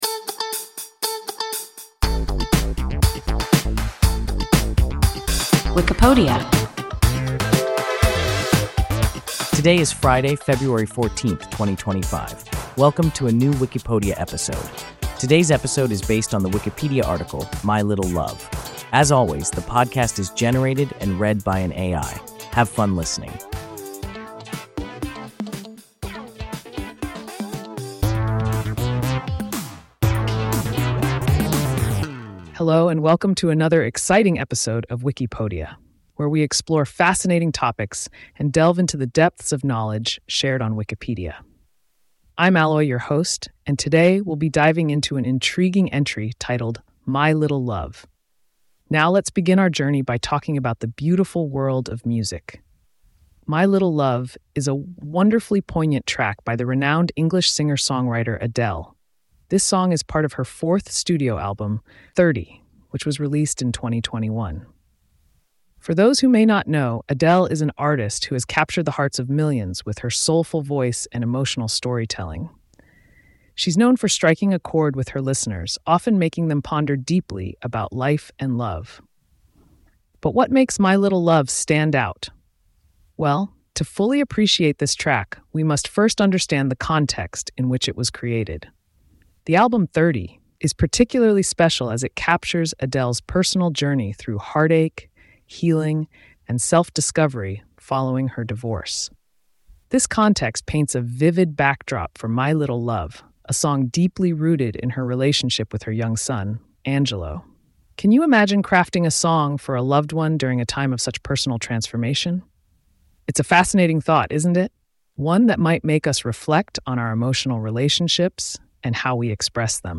My Little Love – WIKIPODIA – ein KI Podcast